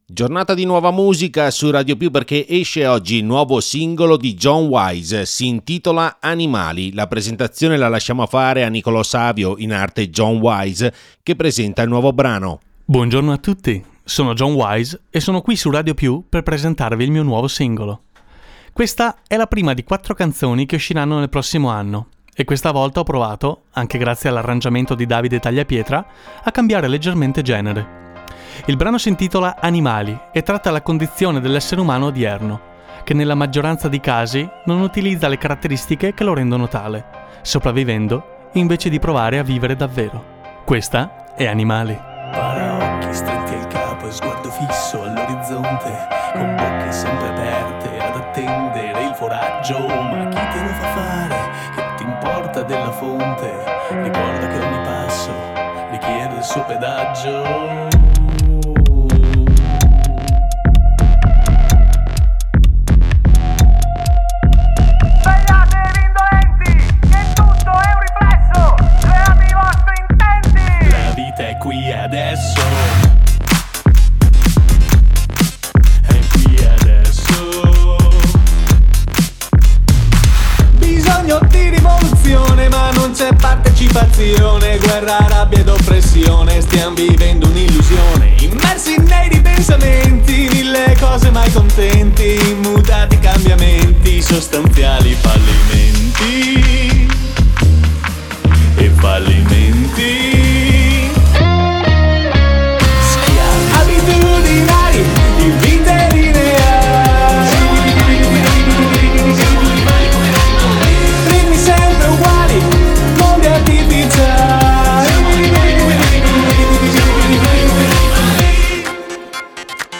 Voce